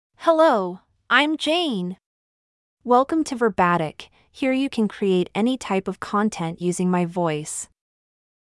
FemaleEnglish (United States)
JaneFemale English AI voice
Jane is a female AI voice for English (United States).
Voice sample
Listen to Jane's female English voice.
Jane delivers clear pronunciation with authentic United States English intonation, making your content sound professionally produced.